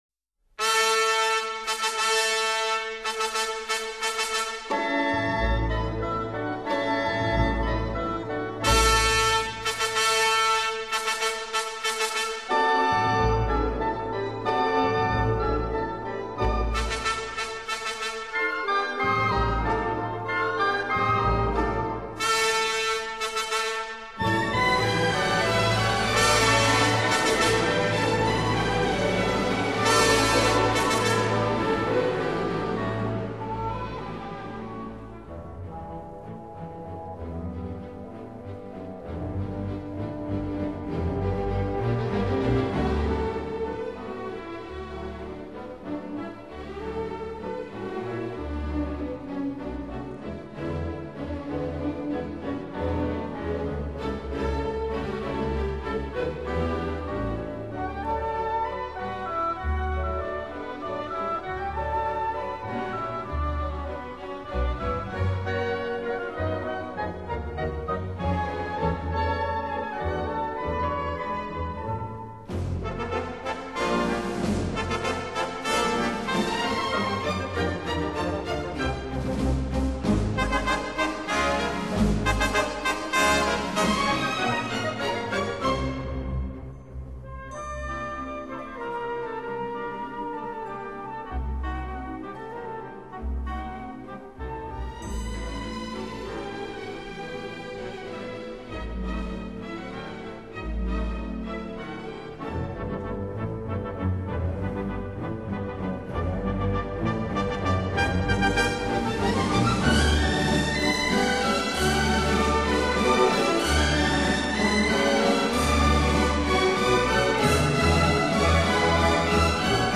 其樂隊作品多採用民歌旋律，同時有複雜的形式。